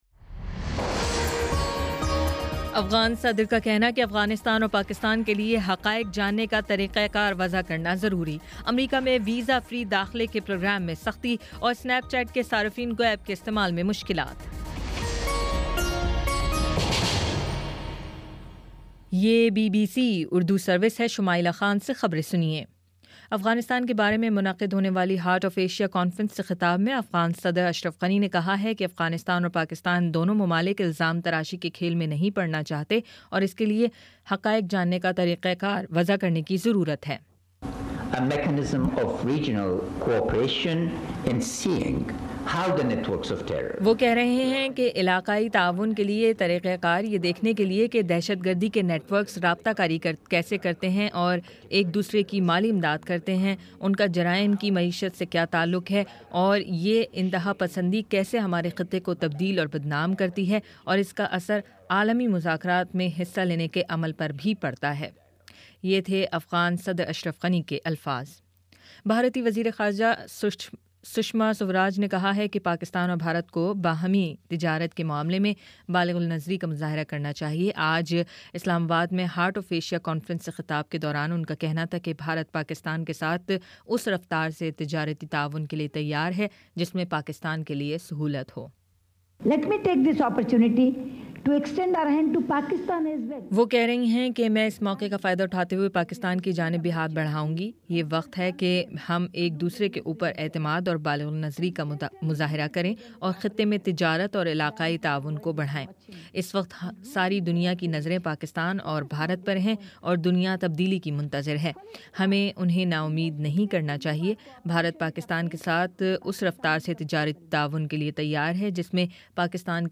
دسمبر 09 : شام چھ بجے کا نیوز بُلیٹن